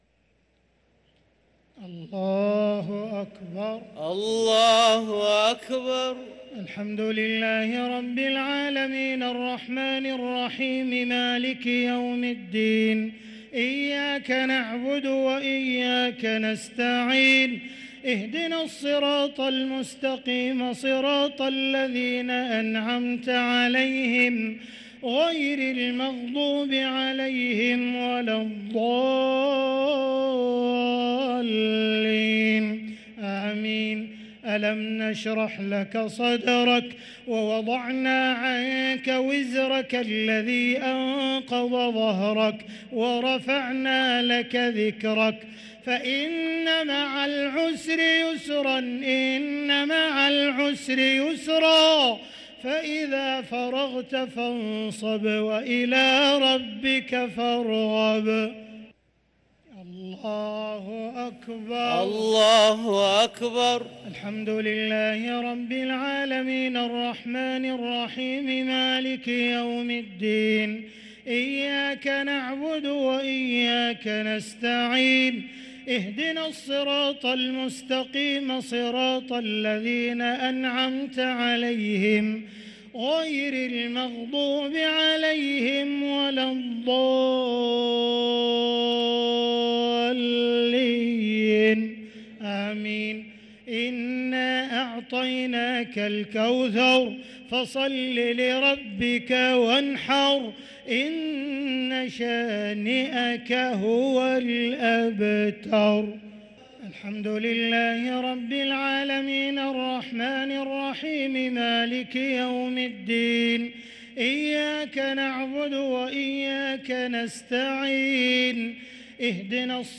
صلاة التراويح ليلة 16 رمضان 1444 للقارئ عبدالرحمن السديس - الشفع والوتر - صلاة التراويح
تِلَاوَات الْحَرَمَيْن .